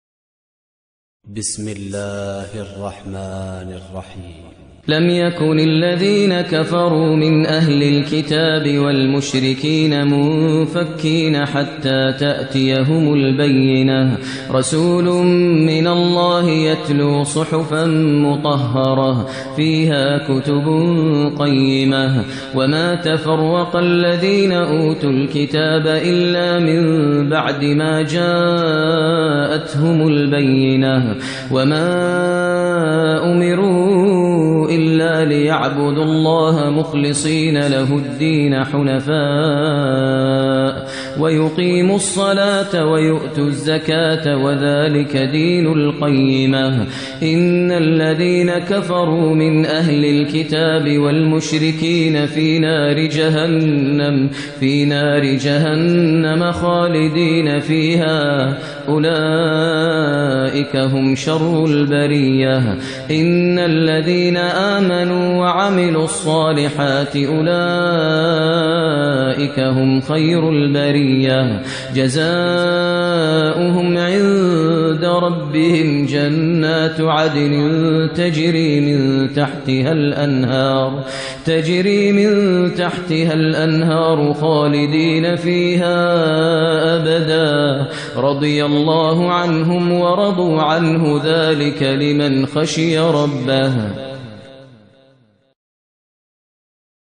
ترتیل سوره بینه با صدای ماهر المعیقلی
098-Maher-Al-Muaiqly-Surah-Al-Bayyina.mp3